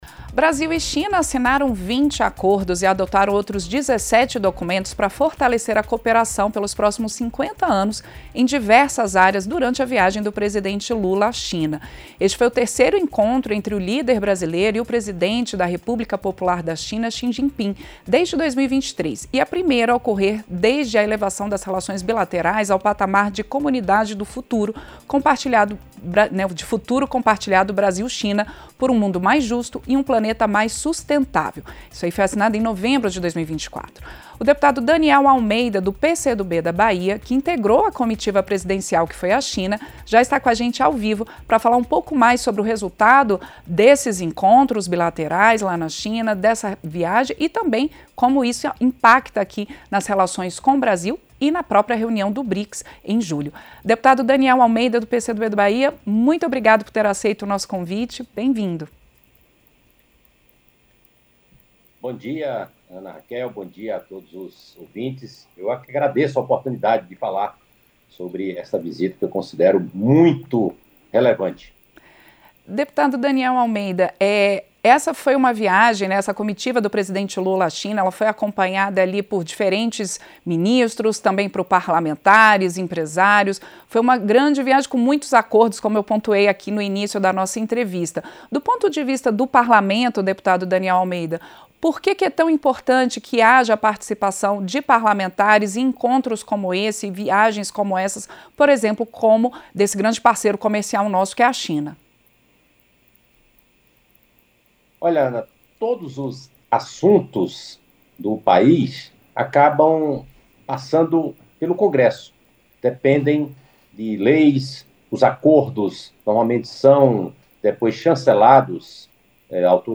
Entrevista - Dep. Daniel Almeida (PCdoB/BA)